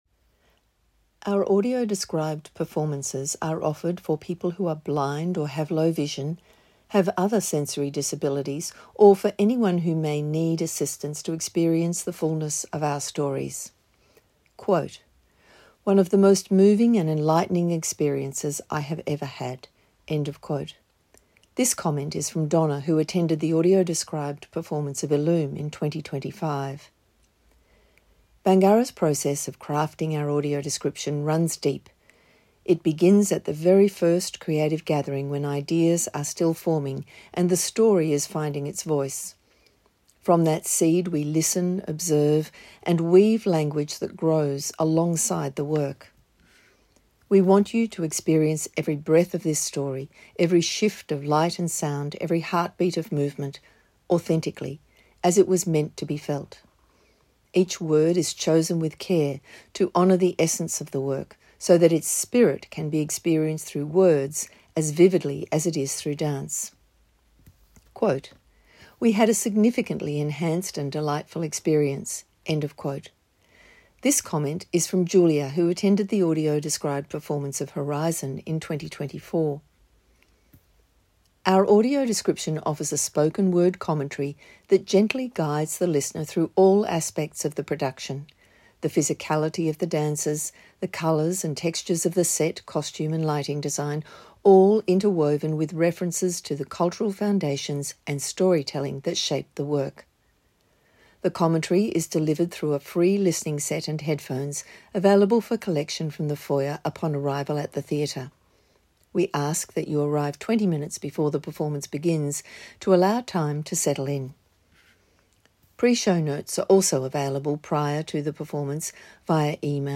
2025-audio-decription-text-to-talk.mp3